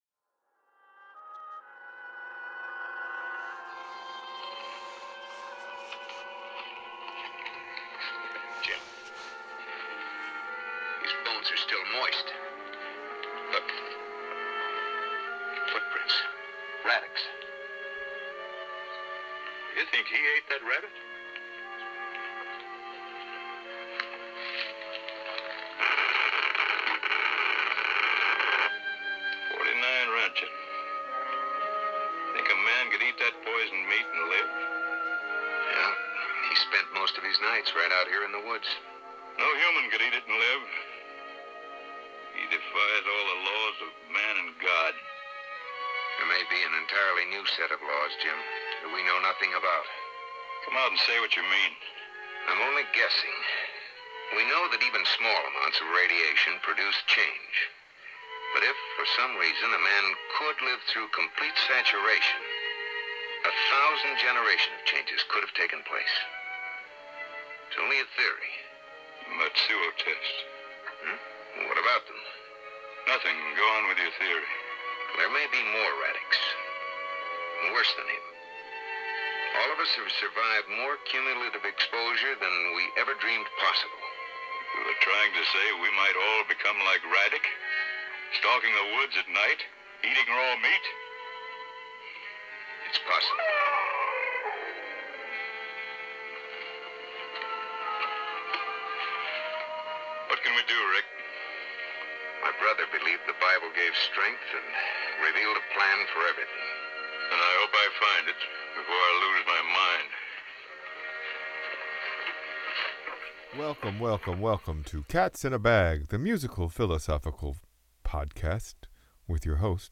Here is a new episode that is really a Frankenstein of two separate attempts…one from October and another from November…all being released in December. There is a faint hiss I could not quite eradicate and of course my levels are probably all over the place. 2025 goals…learn your levels baby.